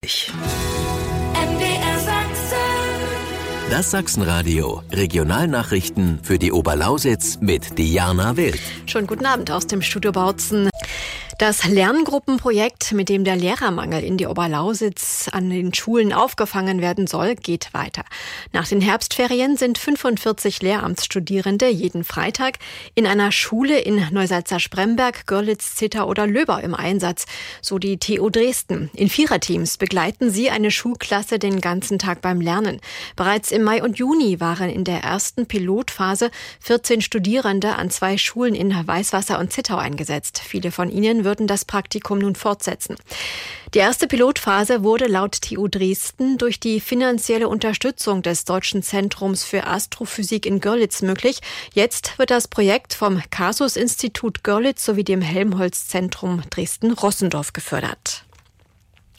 Meldung in den MDR Sachsen Radio Regionalnachrichten Bautzen, 15.10.2024 © MDR Sachsen Radio